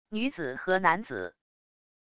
女子和男子wav音频